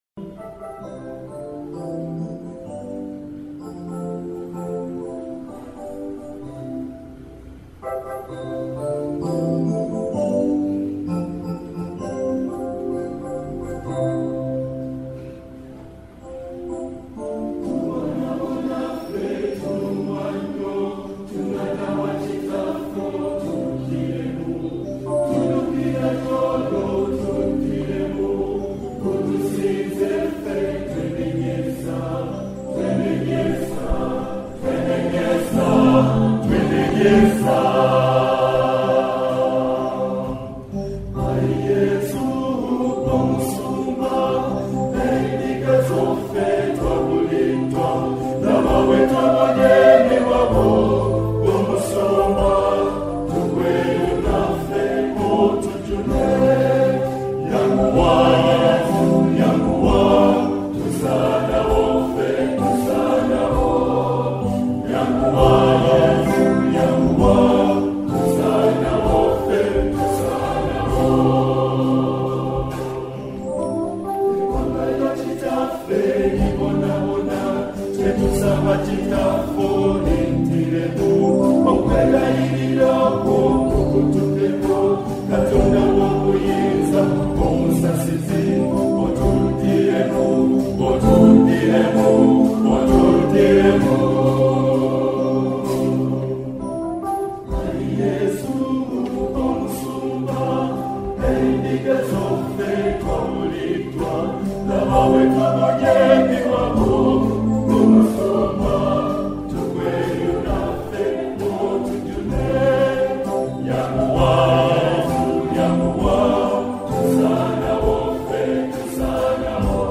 contemporary Catholic liturgical music